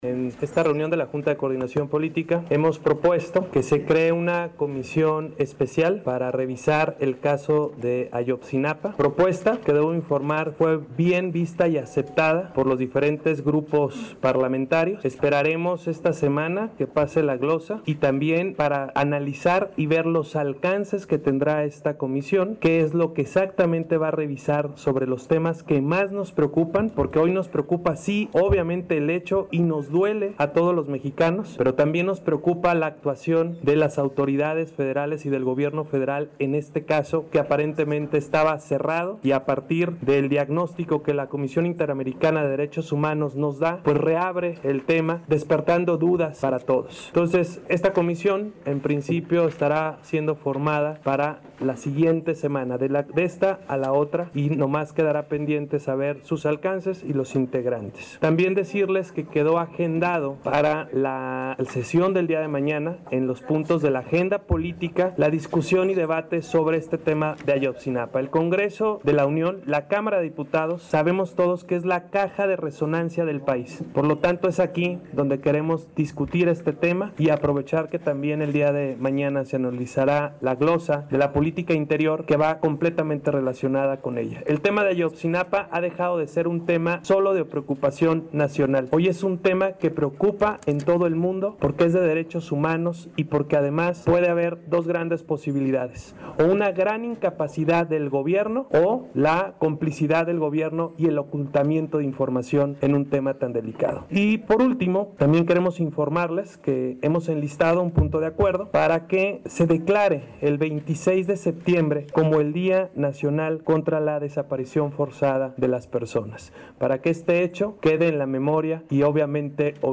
En conferencia de prensa el diputado Marko Cortez Mendoza da a conocer al detalle lo que hará esta comisión.